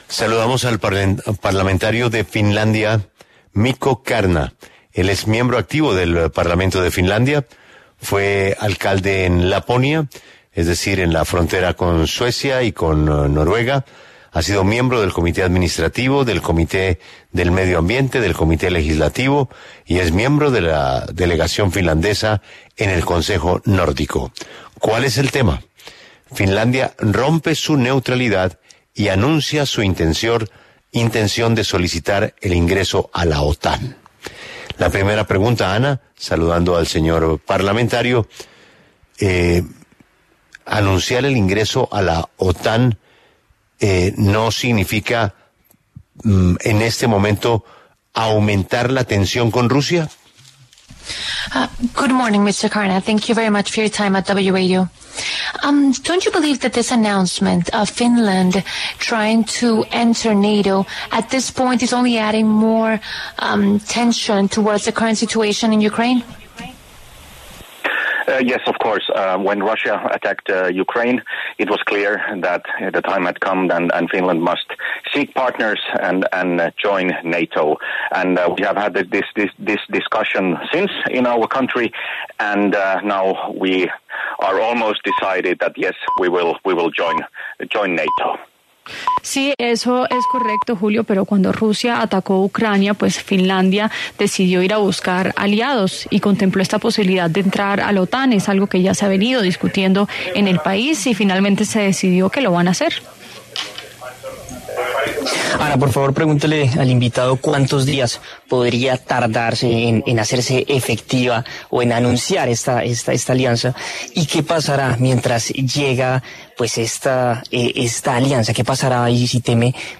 Mikko Kärnä, miembro del Parlamento de Finlandia, habló en La W sobre la intención de su país para entrar a la OTAN, solicitud que se daría la próxima semana, y las repercusiones que trae esta decisión.
Lo invitamos a escuchar la entrevista completa con el parlamentario Mikko Kärnä en el player de la imagen.